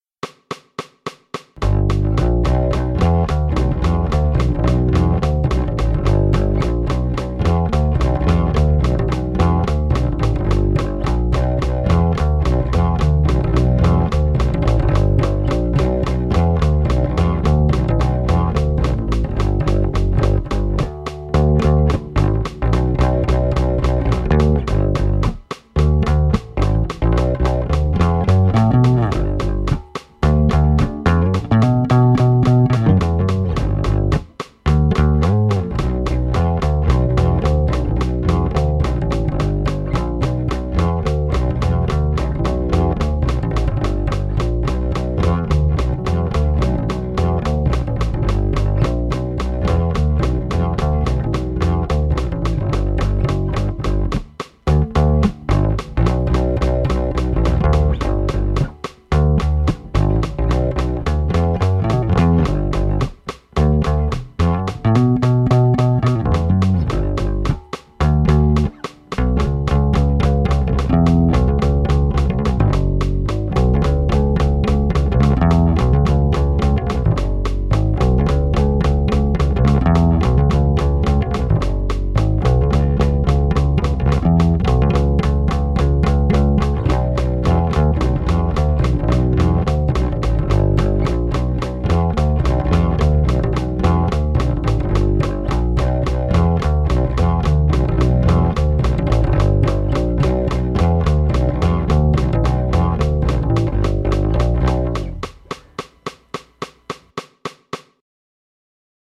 練習曲。
安定させるにはまだまだ基礎の弾き込みが必要っぽい。
例によって例のごとくピックが使えない体質なので、指でぽりぽり弾いてます。